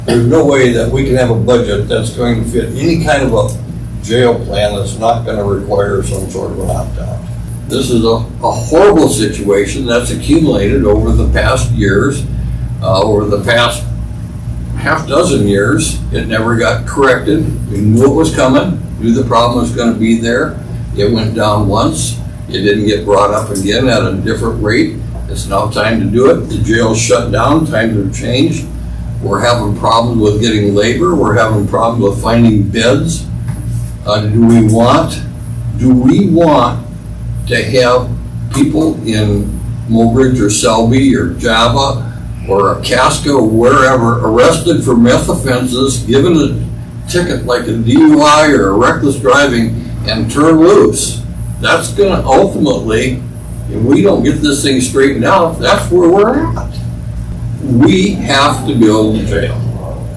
As the July 15th opt out date approaches, the Walworth County Commissioners discussed some of their options for building a jail at their regular meeting on Tuesday, June 15, 2021.
Cain said not opting out is not an option.